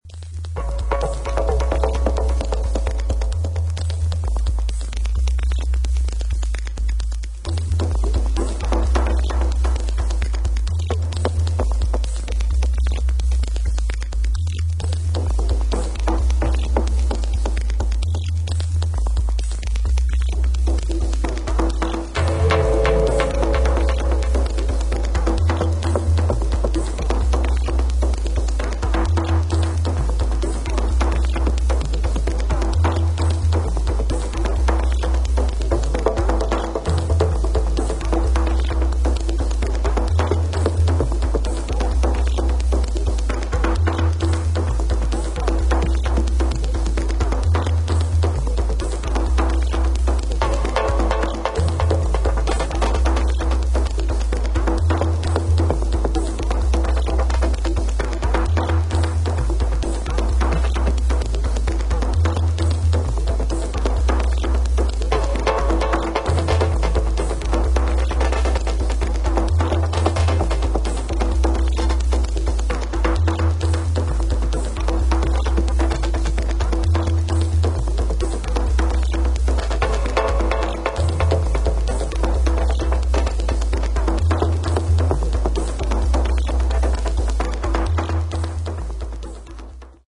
A面をさらにアグレッシブに展開させたB面
両トラックともキックが入らないエクスペリメンタルなディープ ダブ・テクノ作品です。